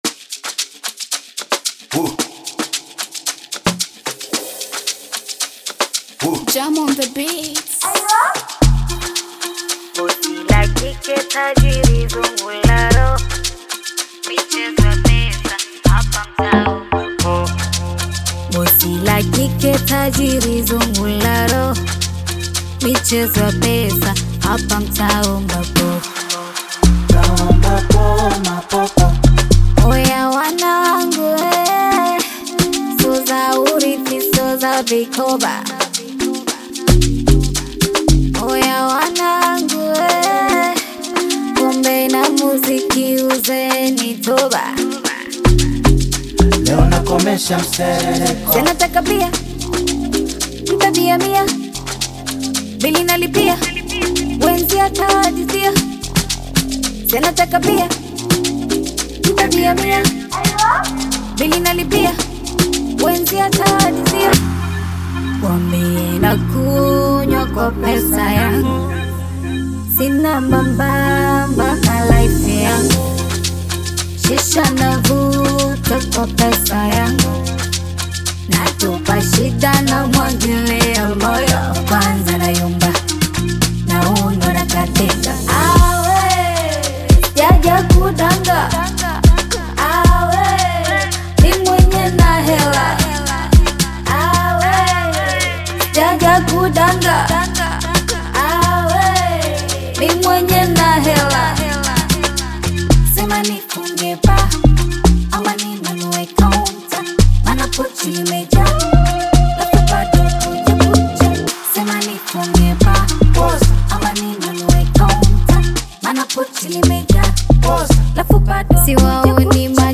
Bongo Amapiano song
Bongo Flava song